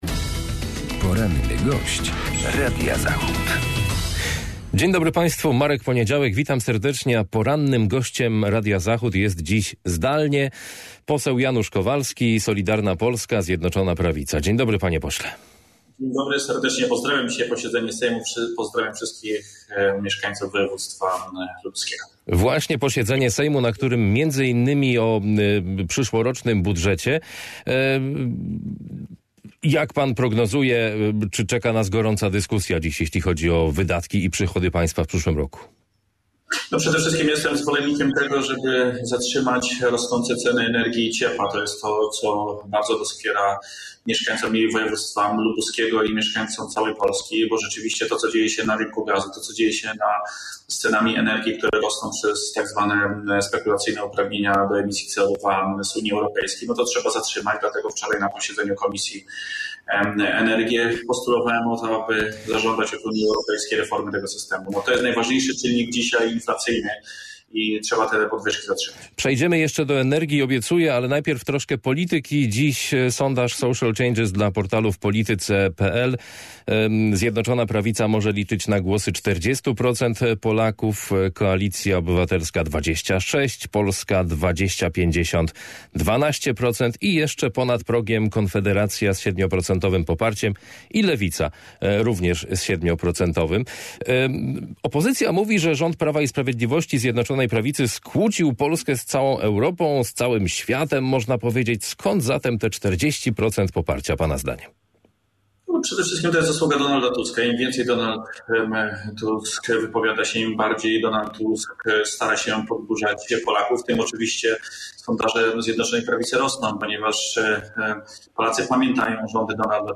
Z posłem Solidarnej Polski rozmawia